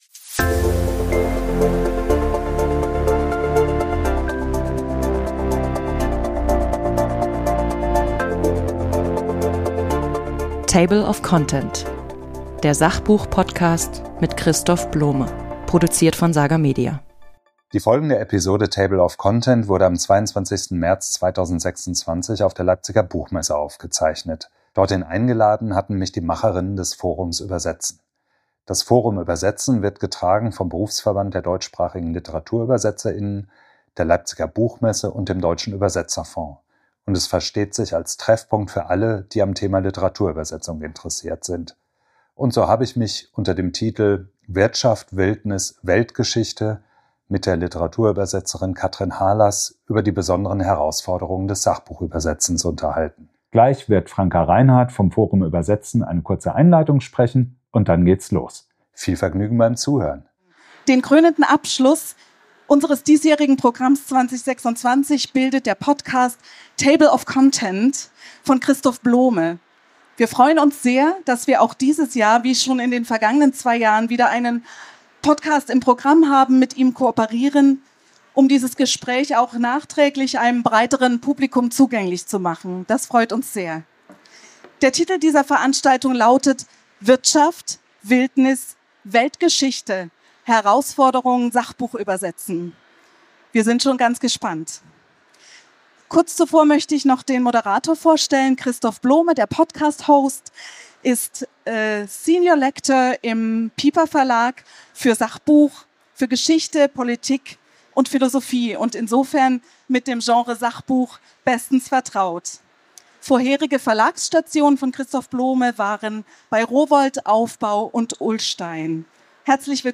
auf dem Forum Übersetzen der Leipziger Buchmesse 2026) ~ Table of Content - der Sachbuch-Podcast